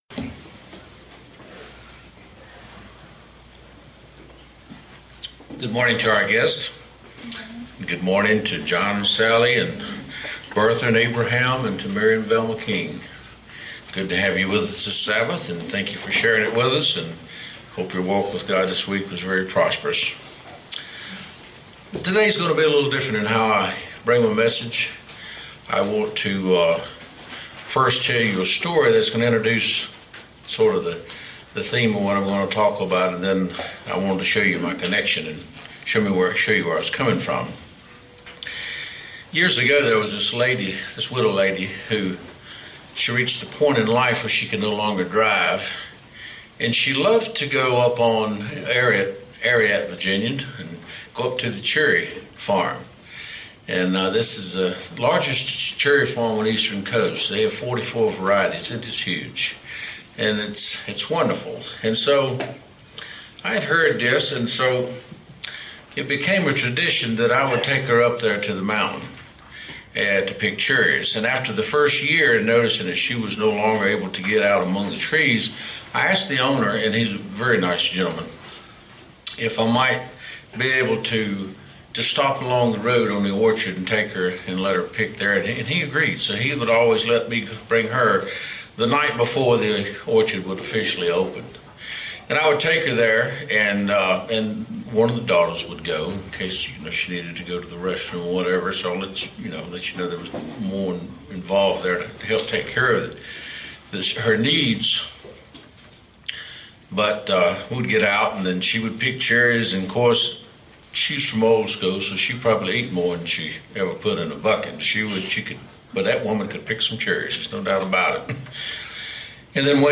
Given in Roanoke, VA